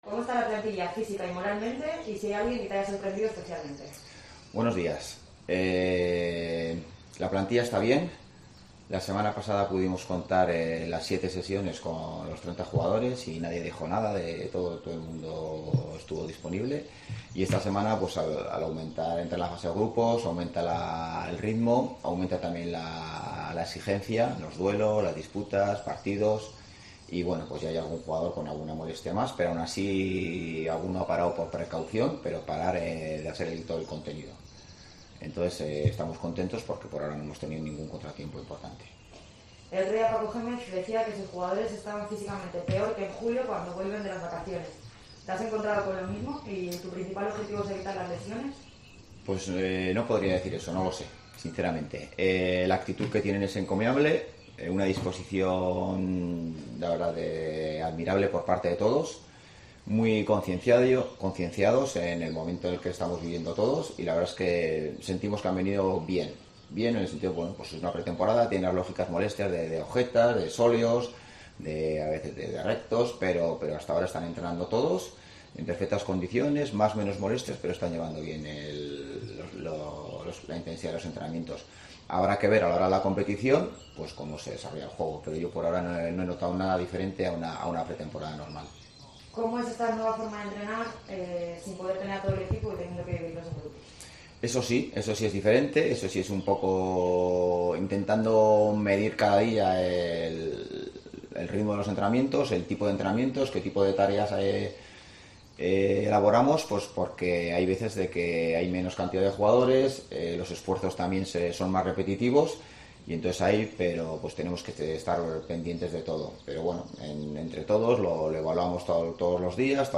El entrenador navarro del Oviedo, José Ángel ‘Cuco’ Ziganda habló en rueda de prensa sobre el regreso a la competición.